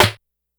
Snare_21.wav